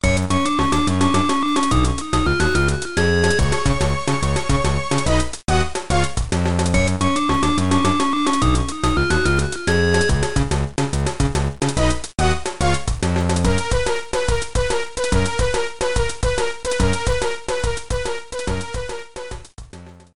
The menu music
Trimmed and fade out